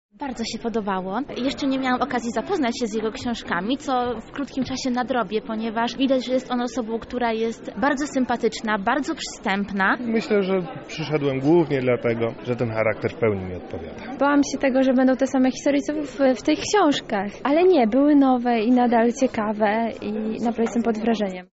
A jak spotkanie oceniają jego uczestnicy?
Uczestnicy
Uczestnicy.mp3